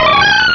cries
misdreavus.aif